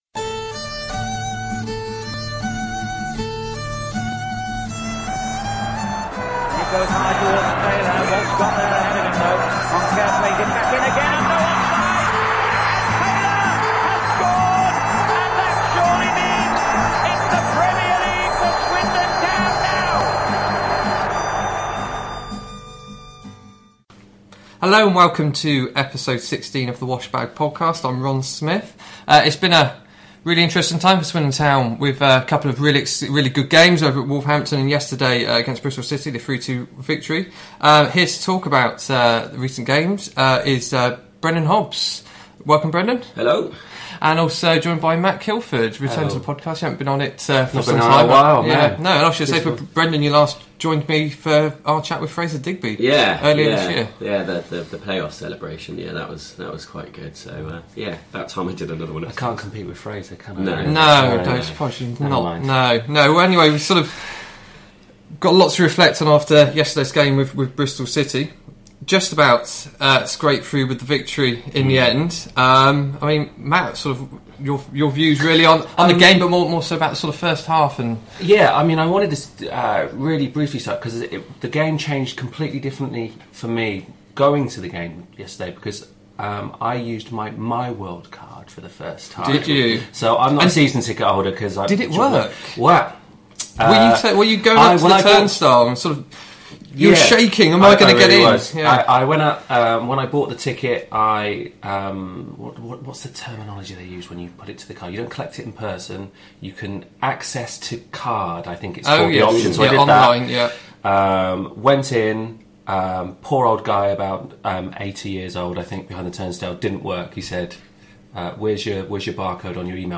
The three review Swindon’s local derby with Bristol City which ended with a 3-2 victory for the hosts and condemned City to yet another defeat. This match witnessed Aden Flint’s return to the County Ground, so we get their view on his goal and reactions to the abuse.